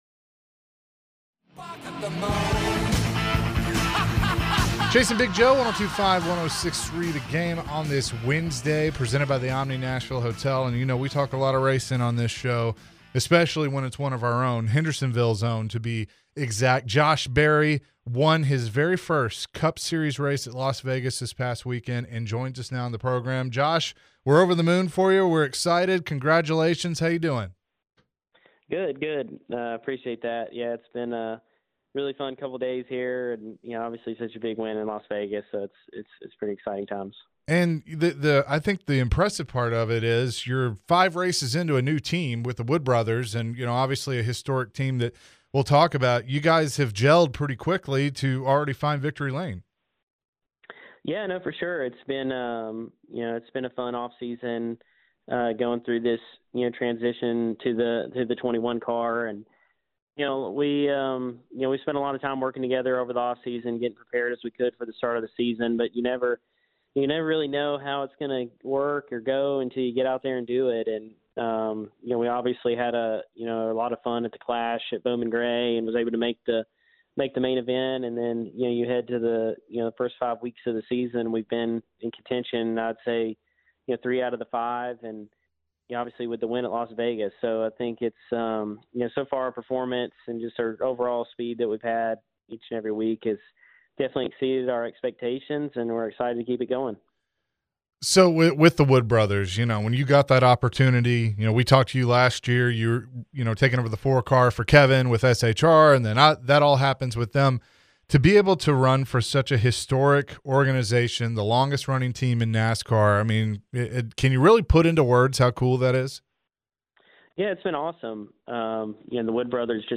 NASCAR Driver Josh Berry joined the show and shared his experience winning his first cup series race. Plus he talked about his journey from being a hometown guy from Hendersonville.